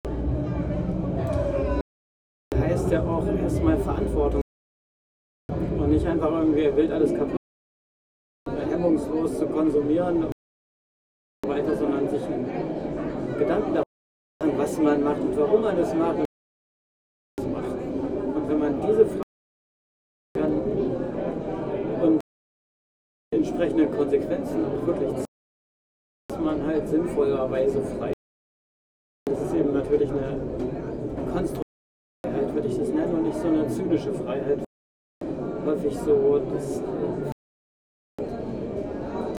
Was heißt für Dich Freiheit [Anmerkung der Redaktion: schlechte Soundqualität]
Stendal 89/90 @ Stendal